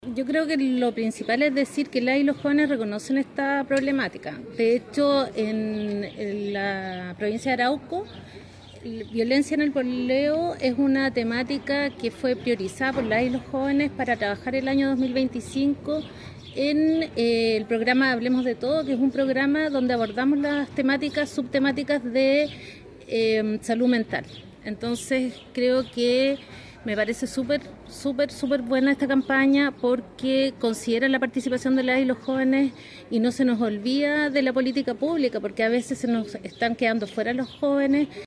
En cuanto a la problemática de fondo, la directora regional del Instituto Nacional de la Juventud, Jimena Jorquera, mencionó que es un tema en el que los mismos jóvenes han demostrado interés en profundizar.